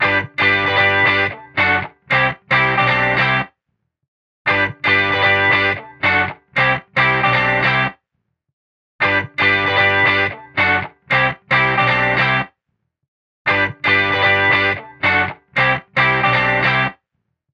Recorded at Beware of Dog Studios - Chicago, IL 2011-2012